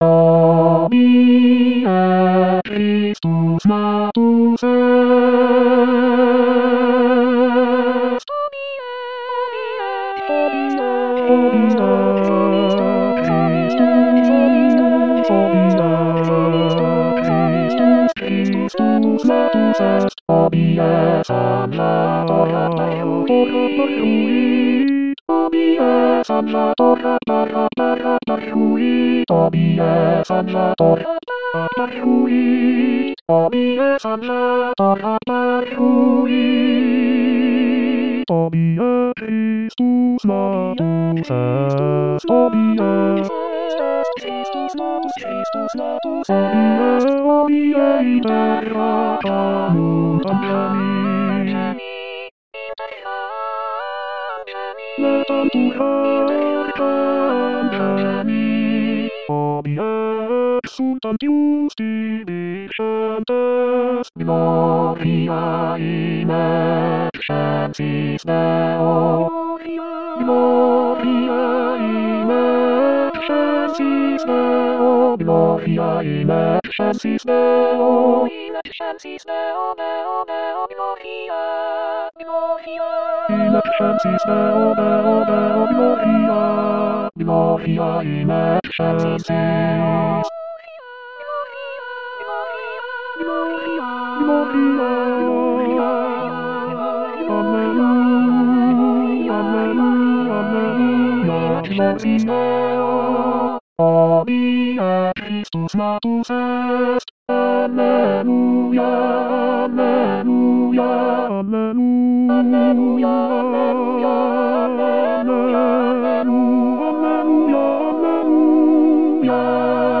Bass Bass 1